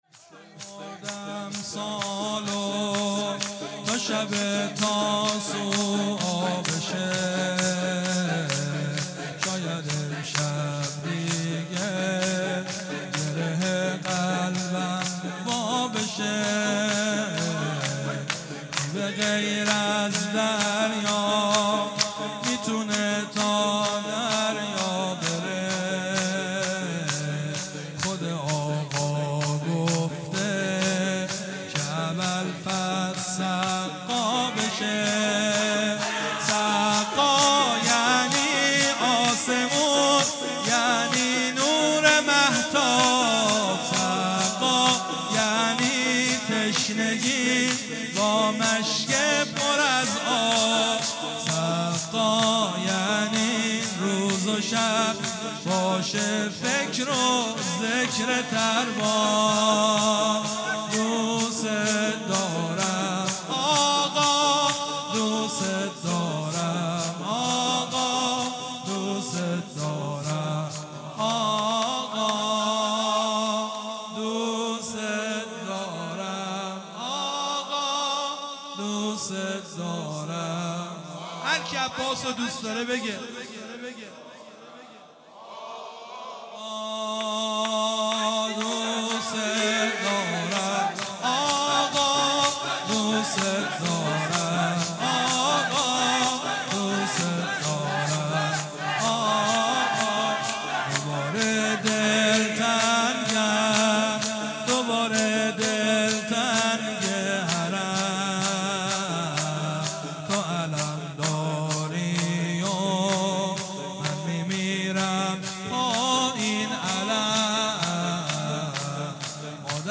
هیئت دانشجویی فاطمیون دانشگاه یزد
شور
شهادت امام حسن مجتبی (ع) | ۱۷ آبان ۱۳۹۵